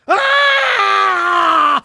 fire_scream2.ogg